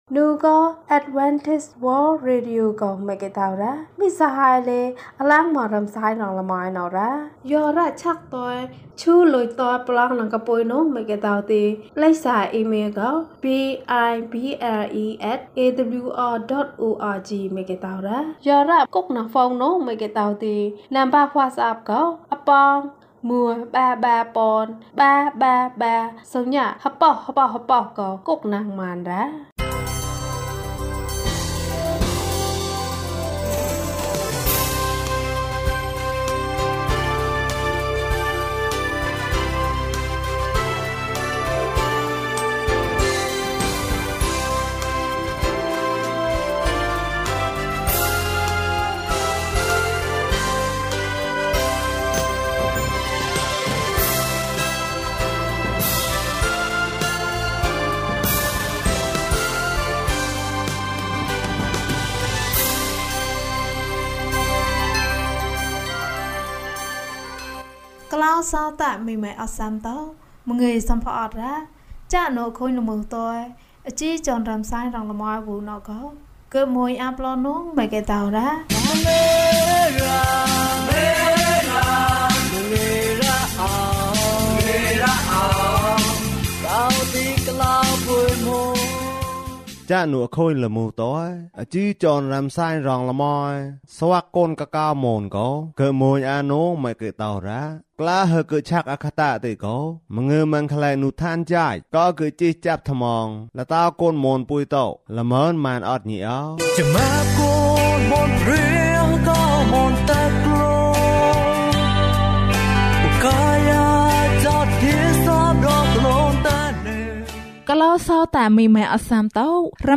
ရှင်ဘုရင်။ ကျန်းမာခြင်းအကြောင်းအရာ။ ဓမ္မသီချင်း။ တရားဒေသနာ။